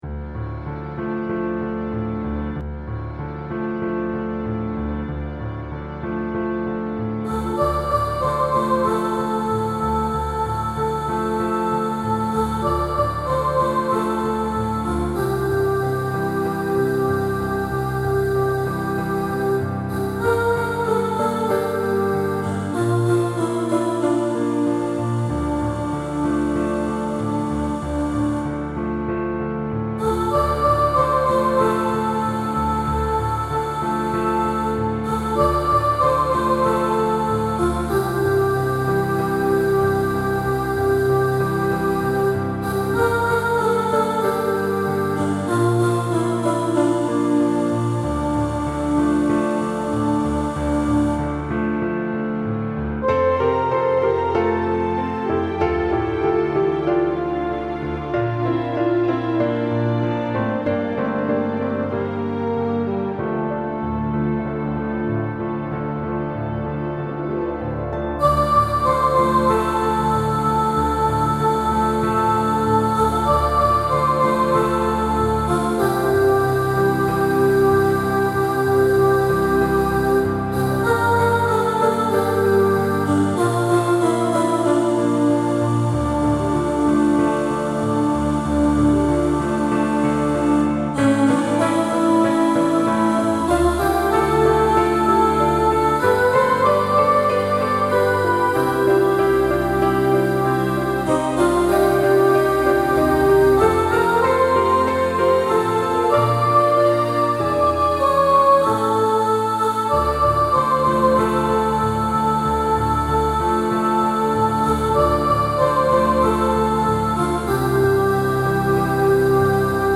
Walking-In-The-Air-Soprano.mp3